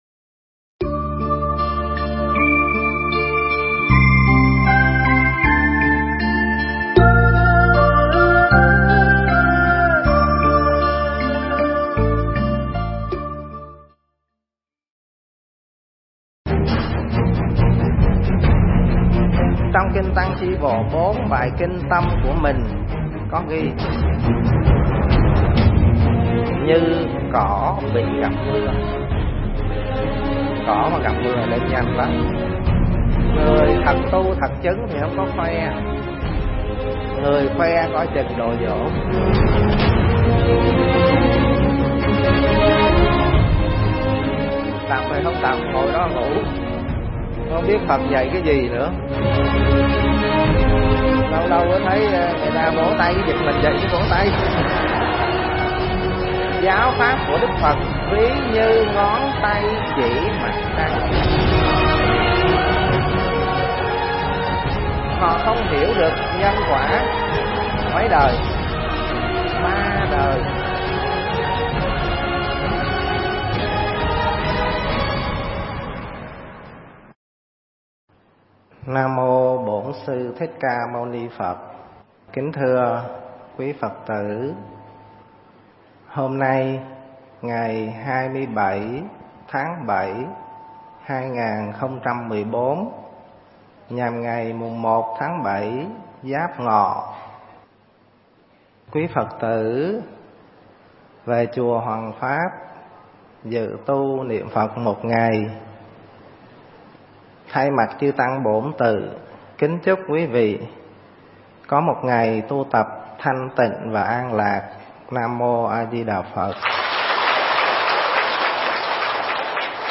Nghe Mp3 thuyết pháp Khéo Biết Tâm Mình